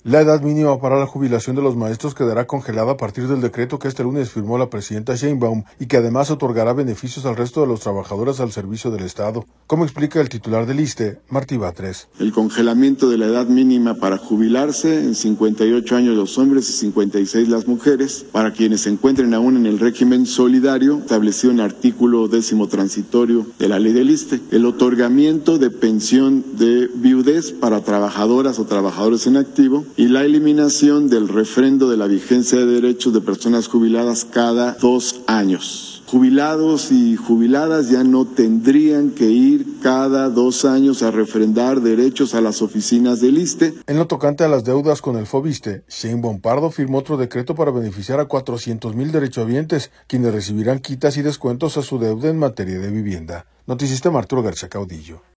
La edad mínima para la jubilación de los maestros quedará congelada a partir del decreto que este lunes firmó la presidenta Sheinbaum y que además otorgará beneficios al resto de los trabajadores al servicio del Estado, como explica el titular del ISSSTE, Martí Batres.